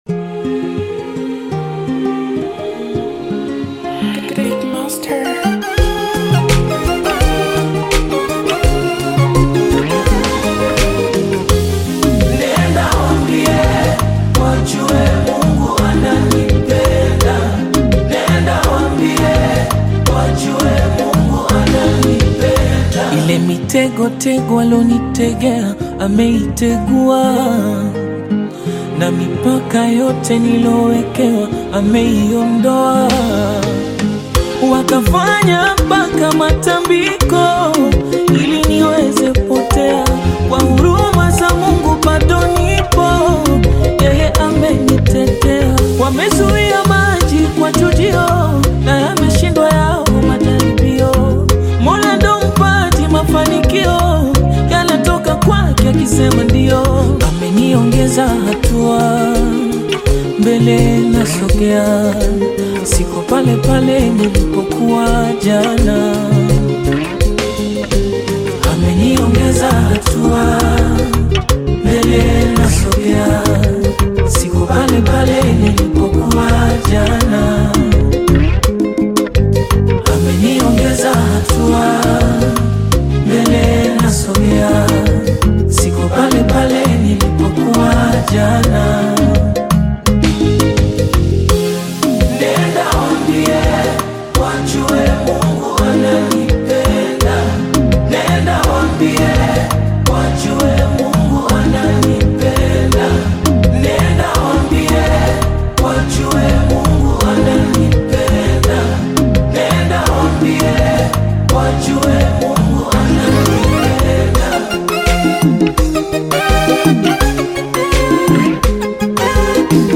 Tanzanian Gospel
Gospel song